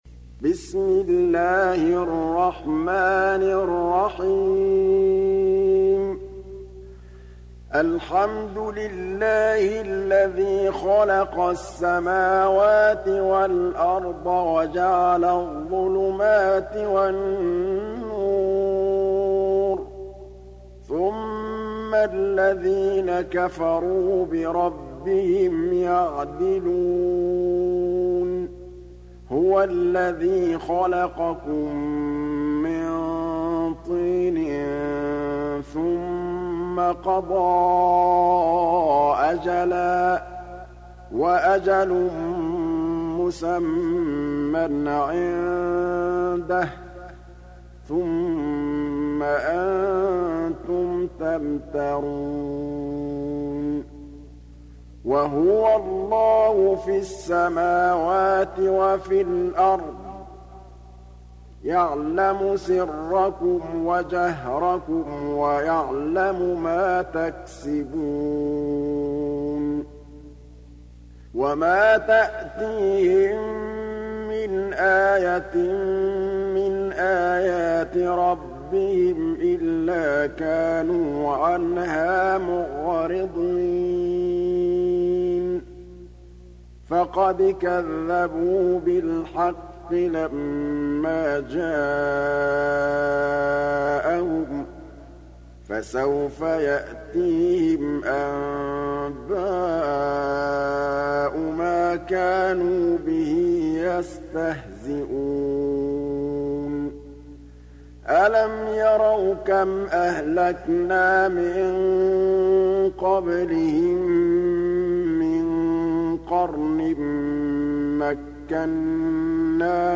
Surah Al Anaam Download mp3 Muhammad Mahmood Al Tablawi Riwayat Hafs from Asim, Download Quran and listen mp3 full direct links